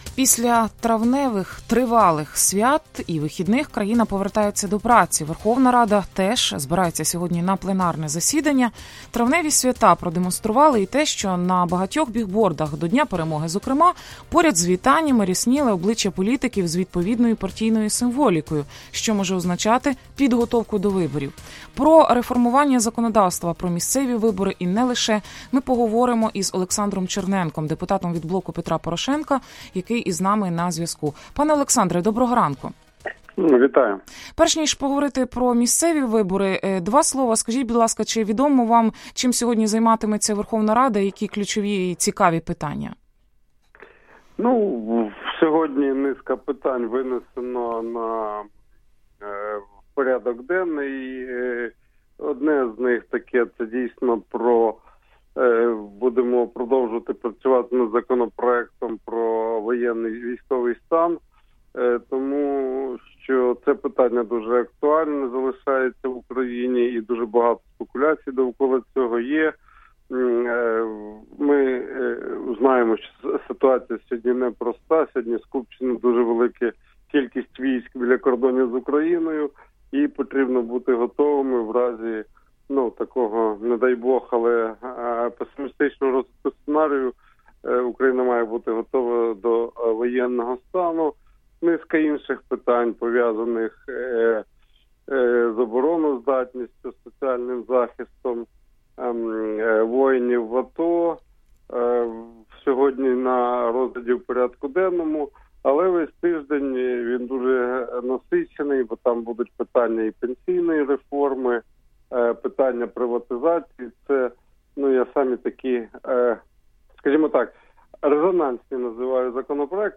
Про це заявив в інтерв’ю Радіо Свобода голова робочої групи при експертній раді з реформування законодавства про місцеві вибори, депутат від «Блоку Петра Порошенка» Олександр Черненко.